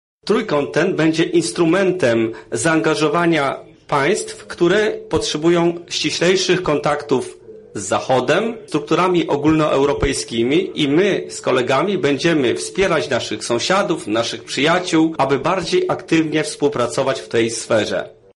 – mówi Minister Spraw Zagranicznych Ukrainy Dmytro Kułeba, który podkreślał również, że podczas wspólnej walki z COVID-19 państwa powinny skupiać się nie tylko, na zwalczaniu choroby, ale również na wspieraniu edukacji, gospodarki i relacji międzyludzkich, które najbardziej ucierpiały w wyniku pandemii.